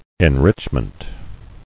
[en·rich·ment]